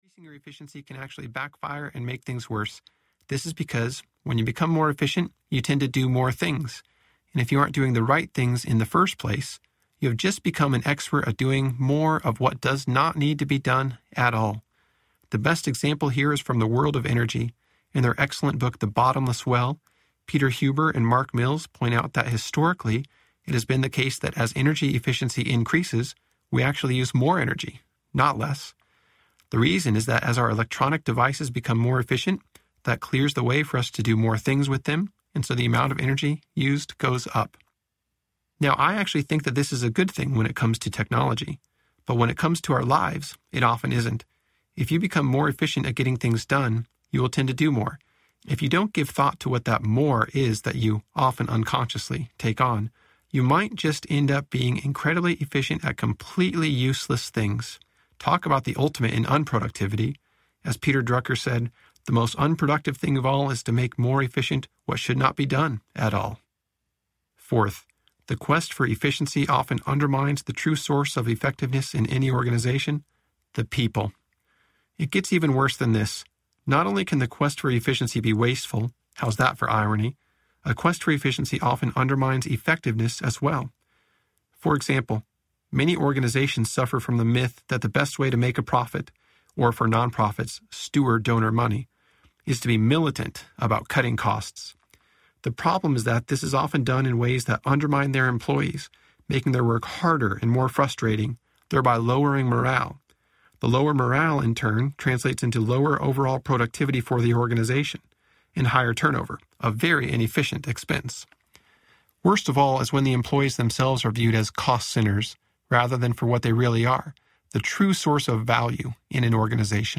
What’s Best Next Audiobook